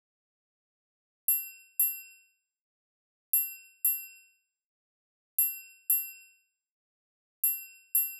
11 Triangle.wav